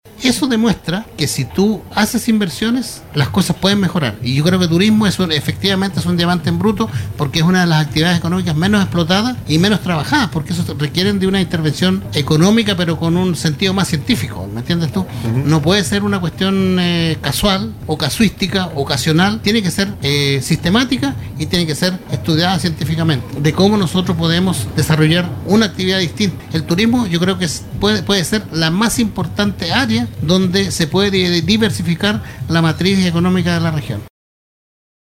un espacio de entrevistas que se realiza en alianza entre Hotel y Casino Antay